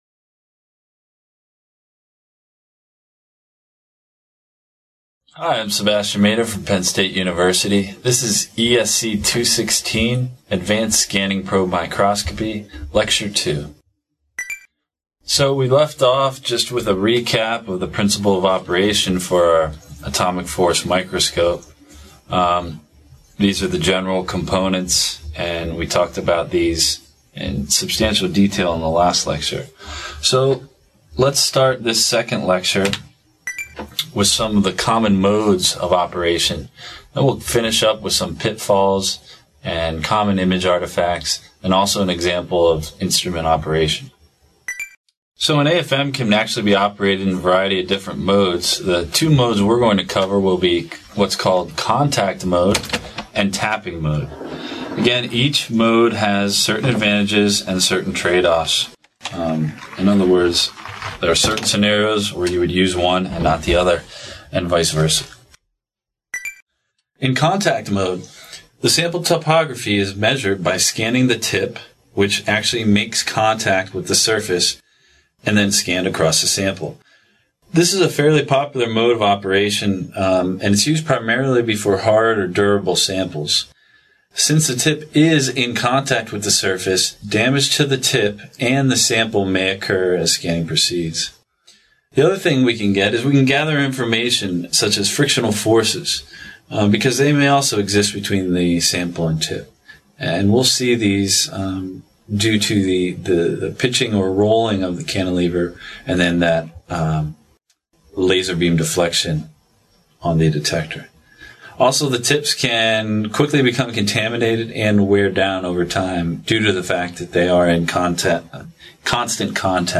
This video, published by the Nanotechnology Applications and Career Knowledge Support (NACK) Center at Pennsylvania State University, is part two of a two-part lecture on advanced scanning probe microscopy for characterization and testing of nanostructures.